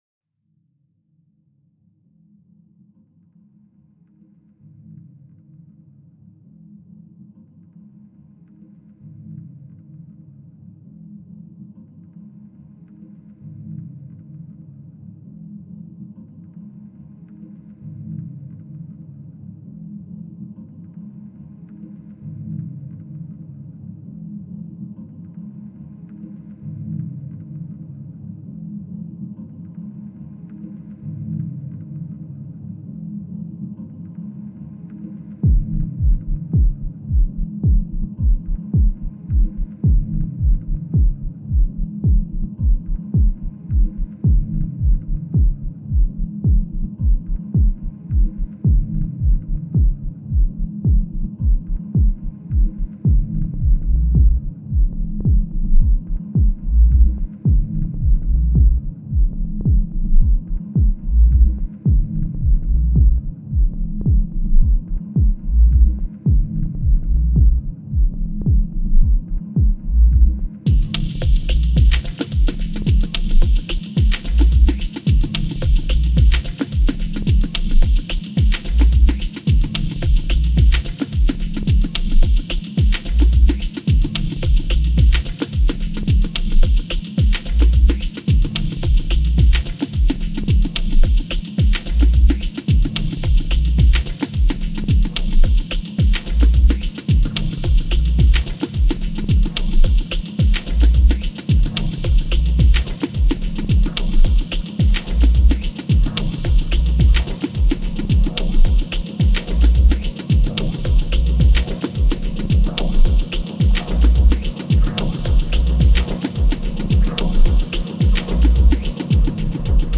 Genre: Deep House/Dub Techno.